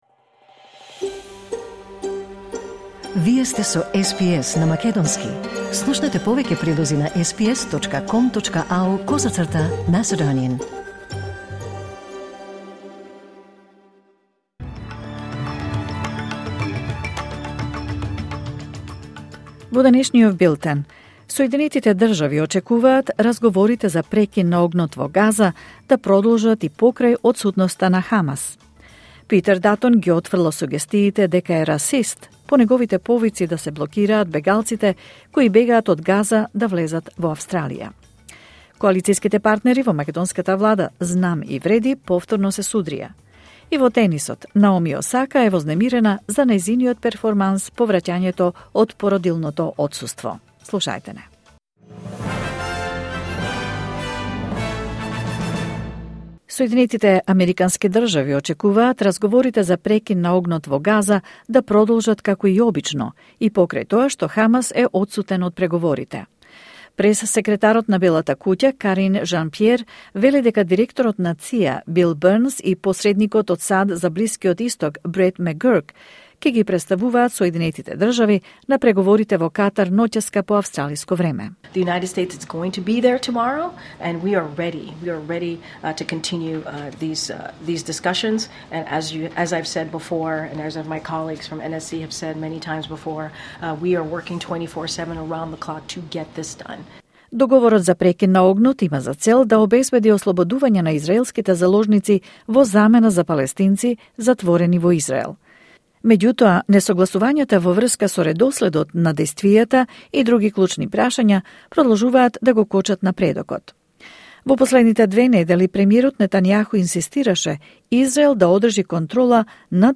SBS News in Macedonian 15 August 2024